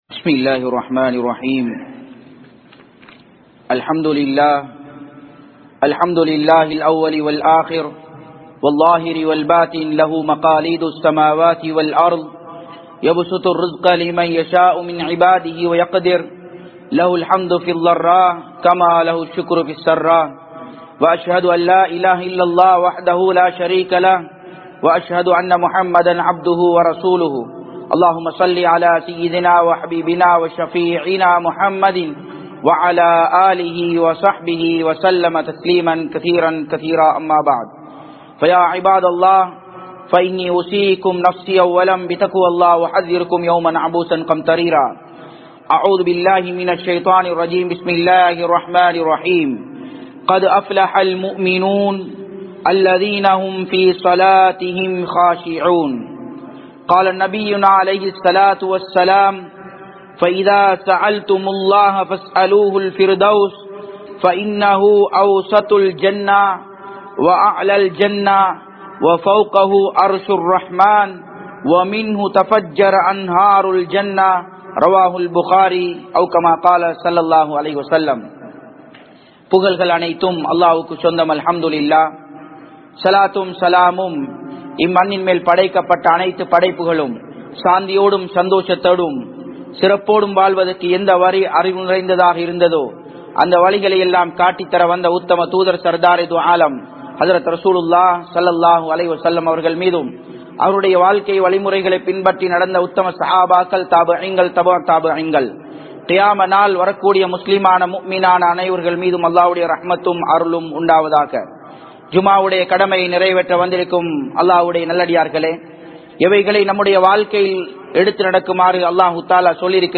Jannathul Firthous ku Thahuthiyaanavarhalin Panpuhal (ஜன்னத்துல் பிர்தௌஸூக்கு தகுதியானவர்களின் பண்புகள்) | Audio Bayans | All Ceylon Muslim Youth Community | Addalaichenai